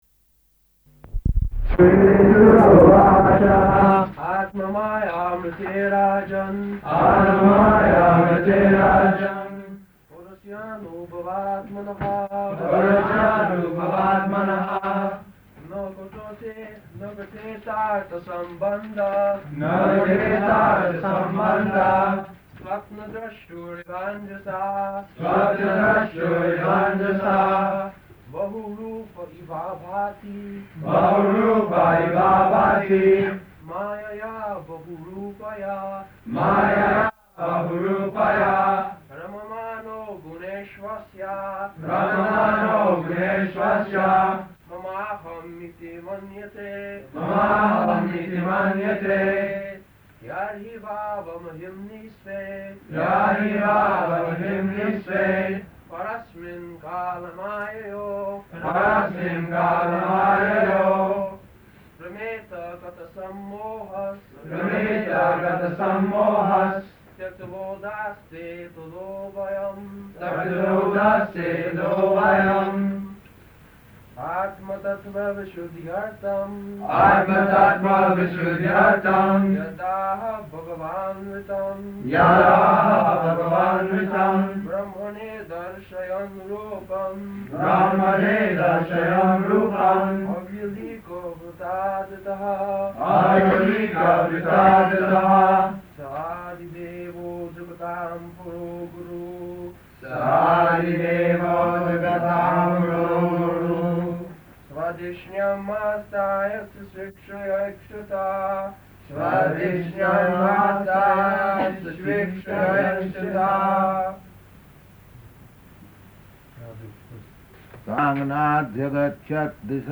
Location: Tokyo
[leads chanting of verse] [devotees repeat]
[repeats verse, correcting pronunciation]